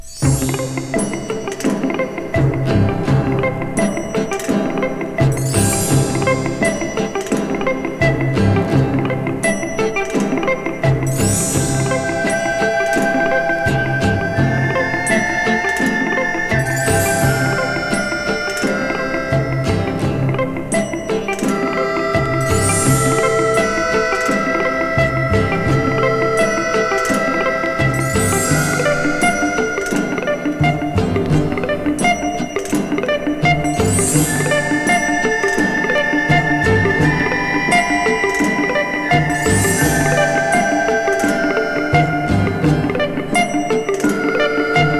Jazz, Pop, Easy Listening　USA　12inchレコード　33rpm　Stereo